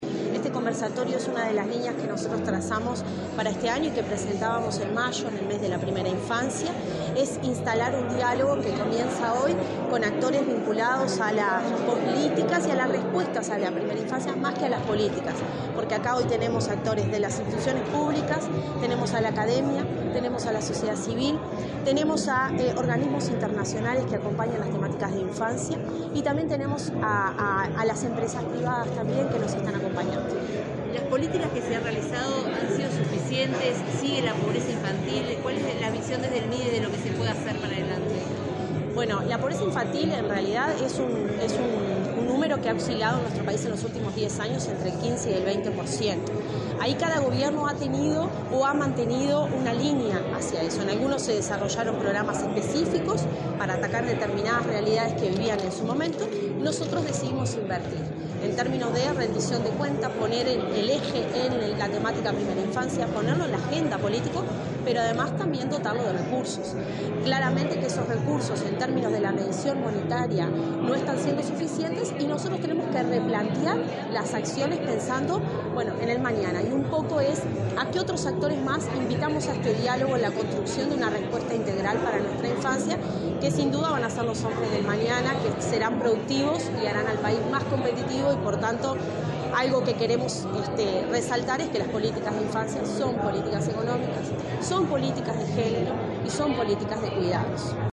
Declaraciones de la directora de Desarrollo Social del Mides, Cecilia Sena
La directora de Desarrollo Social del Ministerio de Desarrollo Social (Mides), Cecilia Sena, dialogó con la prensa en Montevideo, antes de participar